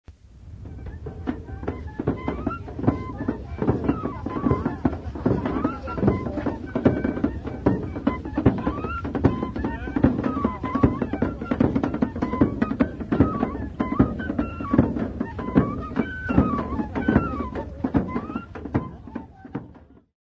O toque do sino é recorrente na festa.
festa_do_rosario-pifanos-f01b.mp3